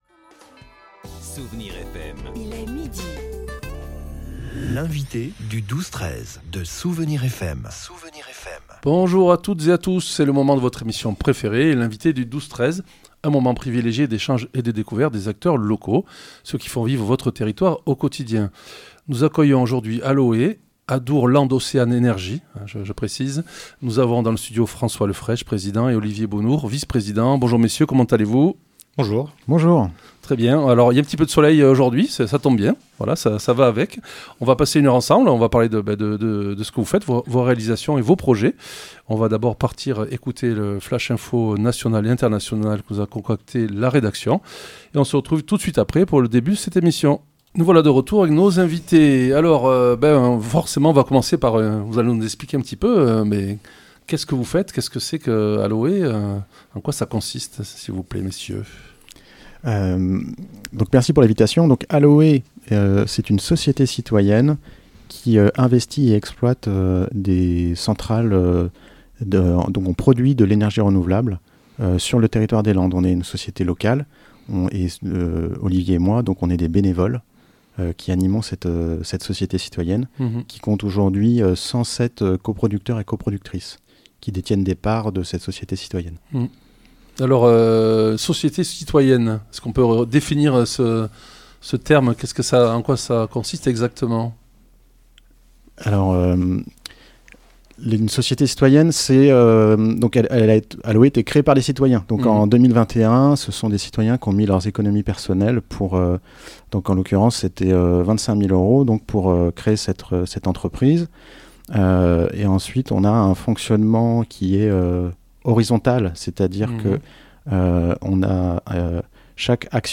L'invité(e) du 12-13 de Soustons recevait aujourd'hui ALOÉ.